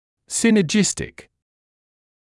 [ˌsɪnəˈdʒɪstɪk][ˌсинэˈджистик]синергичный, действующий совместно